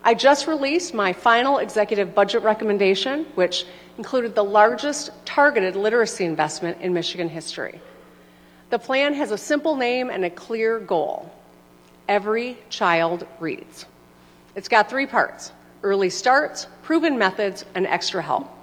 AUDIO: Governor Whitmer delivers her final State of the State address
LANSING, MI (WKZO AM/FM) – Governor Gretchen Whitmer has delivered her eighth and final State of the State address to a joint session of the Michigan Legislature.